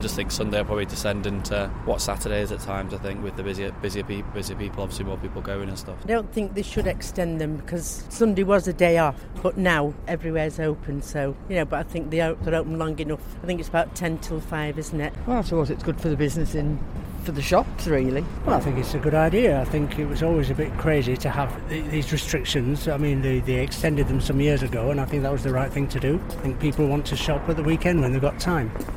Manchester shoppers